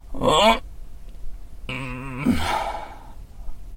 "We brought a high-power scope and parabolic audio amplifier to bear on the creature," said a professor from the university.